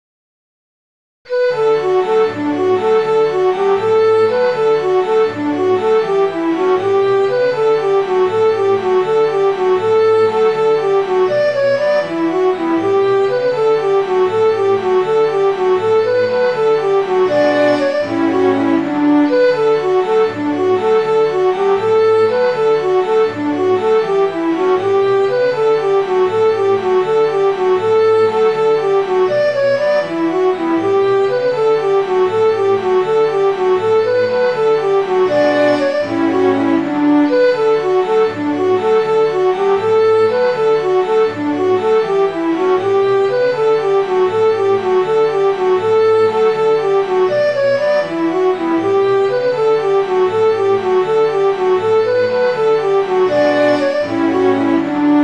Midi File, Lyrics and Information to How Happy the Soldier